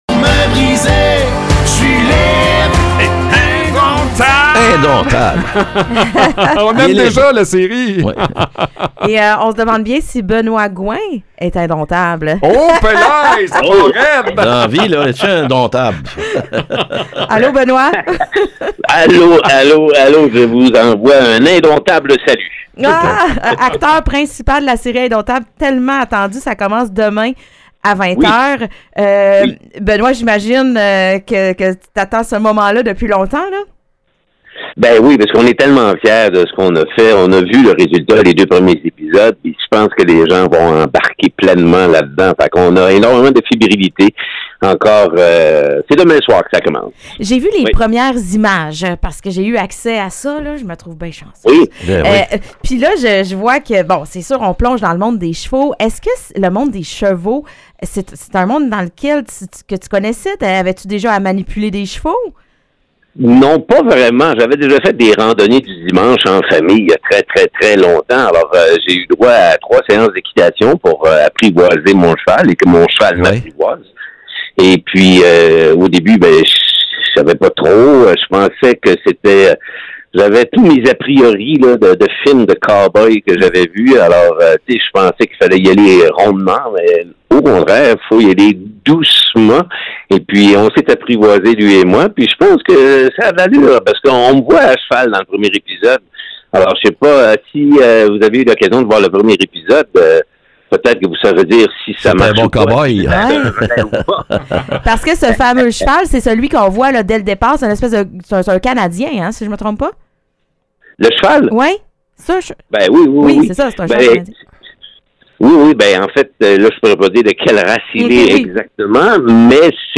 On parle avec l’acteur principal d’Indomptables